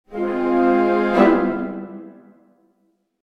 Orchestral Dramatic Accent Sound Effect
An orchestral accent for a dramatic event or cue in a cinematic feature or game. This mysterious two-tone symphonic stinger adds suspense, tension, and impact to your scenes. Perfect for trailers, animations, or any project needing a high-quality dramatic hit.
Orchestral-dramatic-accent-sound-effect.mp3